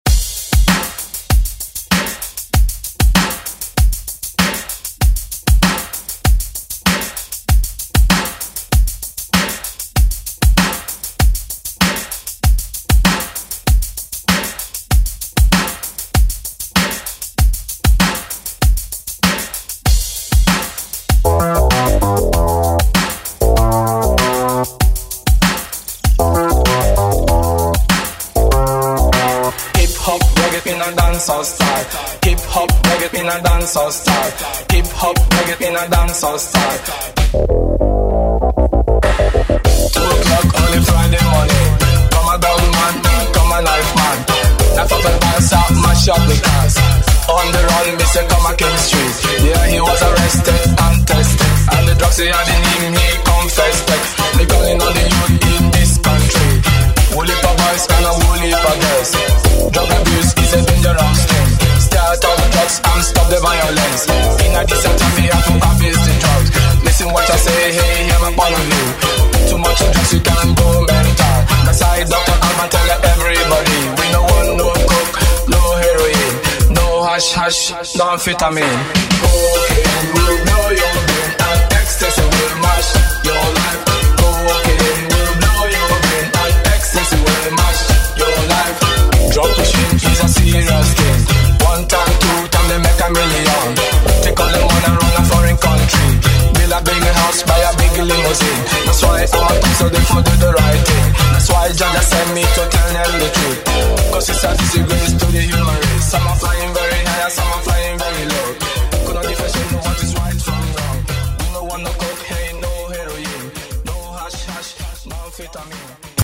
132bpm CLEAN
Throwback Electronic Hi-NRG Disco Music
Extended Intro Outro